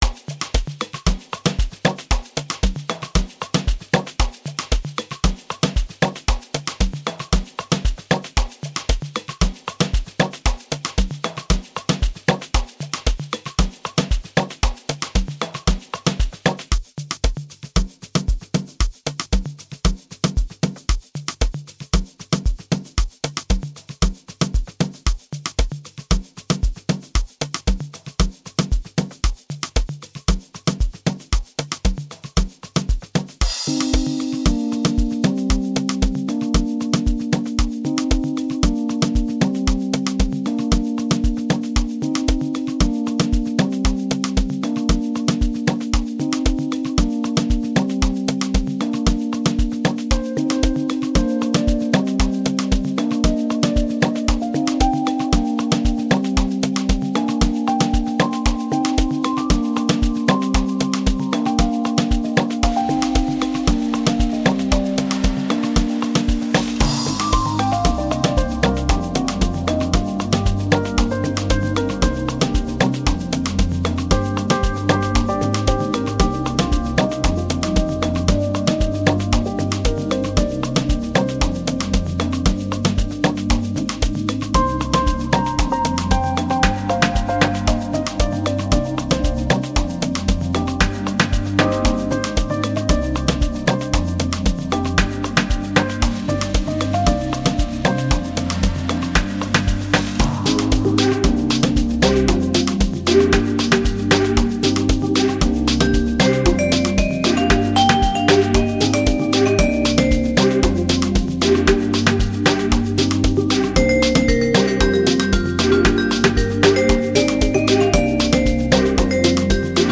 piano inspired hit